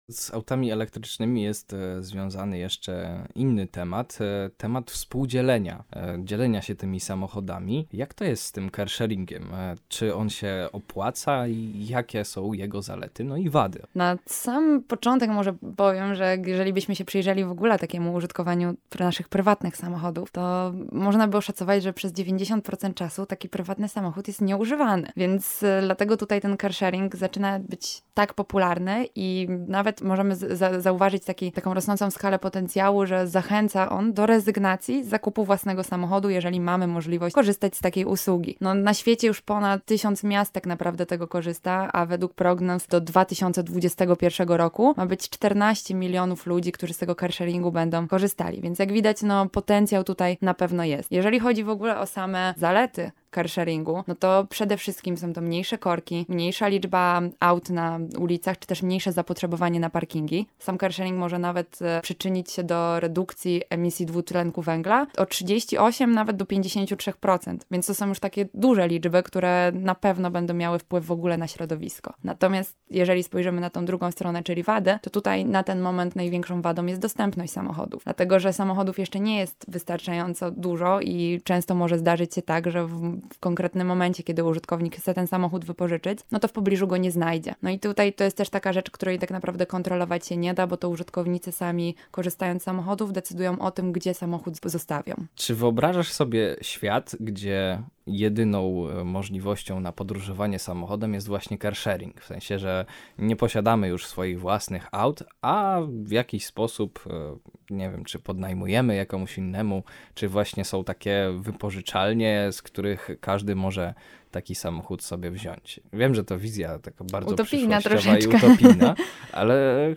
Rozmowy: